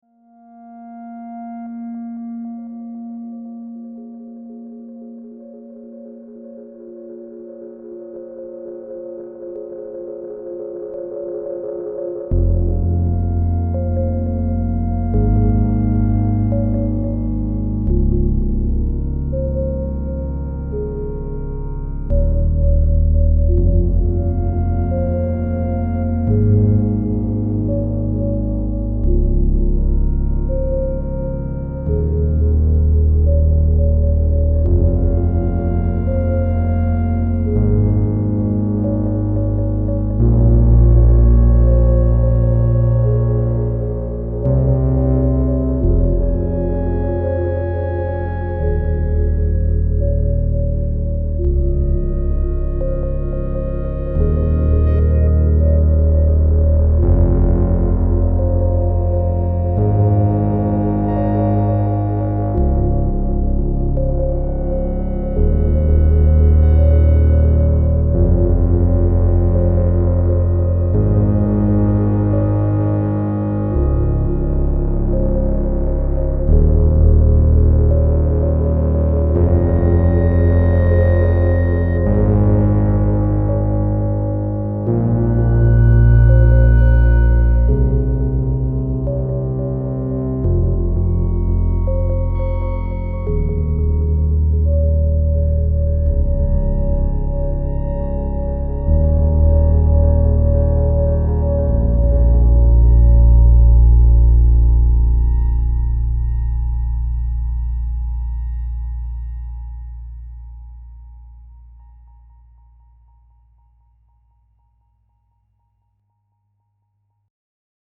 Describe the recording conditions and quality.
Matriarch overdub exercise. OTO BAM used for reverb.